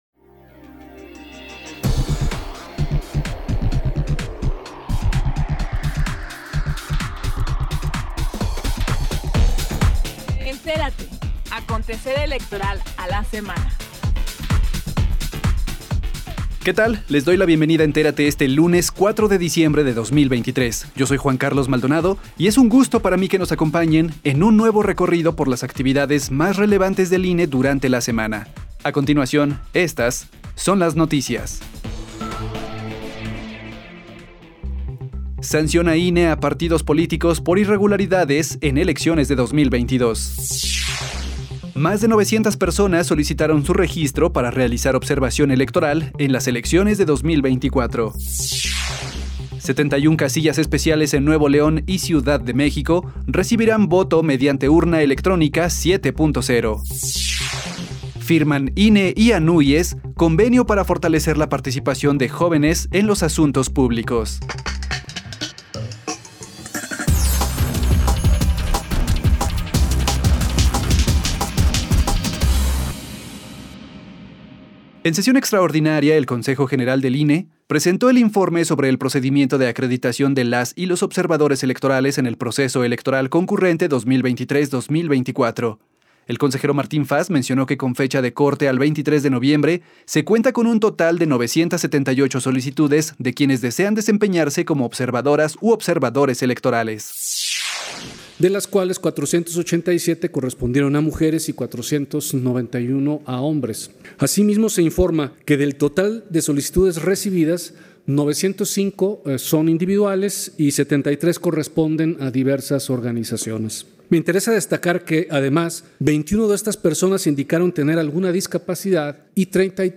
NOTICIARIO-04-DE-DICIEMBRE-DE-2023